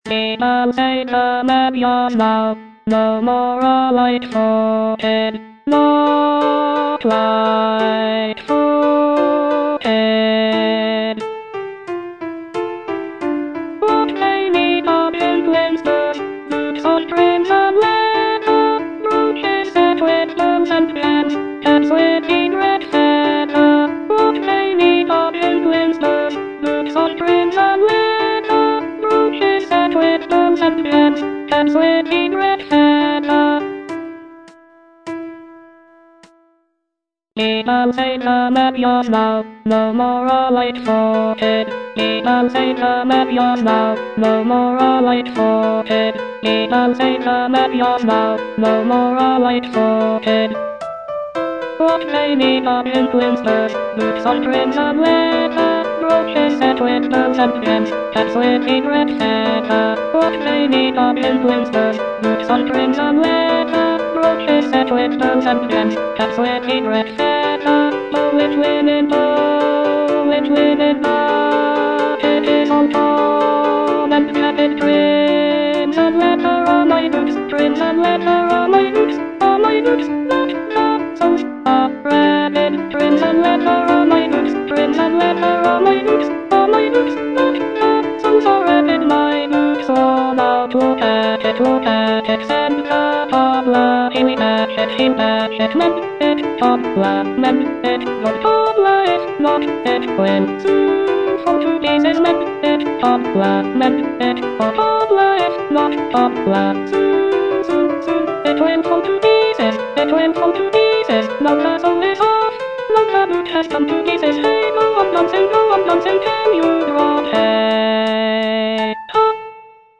Alto I (Voice with metronome)